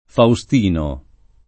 vai all'elenco alfabetico delle voci ingrandisci il carattere 100% rimpicciolisci il carattere stampa invia tramite posta elettronica codividi su Facebook Faustino [ fau S t & no ; sp. fau S t & no ] pers. m.; f. -na (it. e sp.)